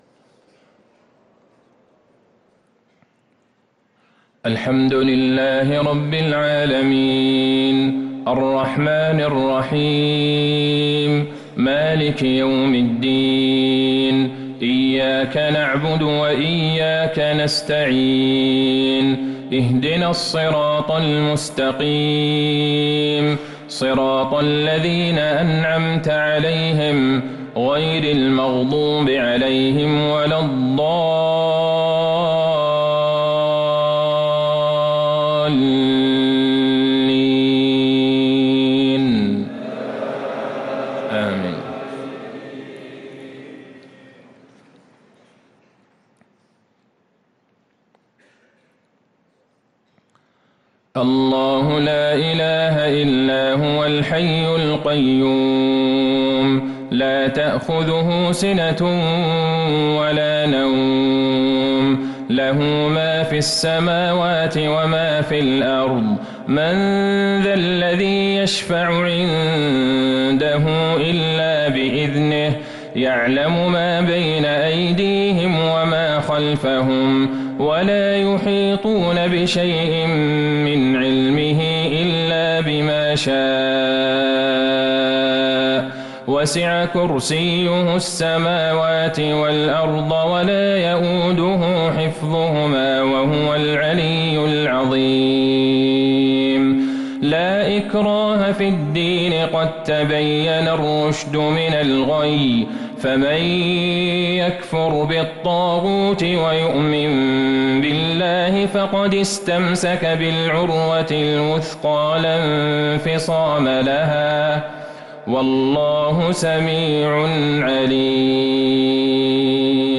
صلاة المغرب للقارئ عبدالله البعيجان 5 ربيع الآخر 1445 هـ
تِلَاوَات الْحَرَمَيْن .